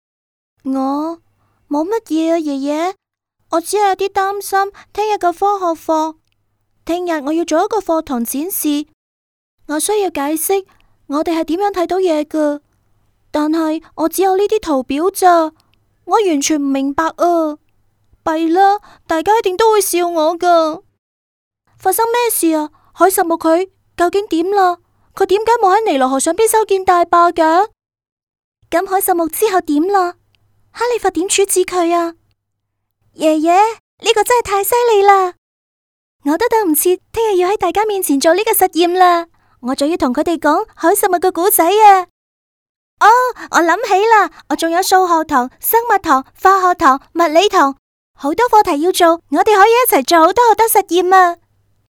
配音风格： 科技 亲切 欢快 磁性 优雅 成熟 时尚 甜美 知性 年轻 甜美 知性 年轻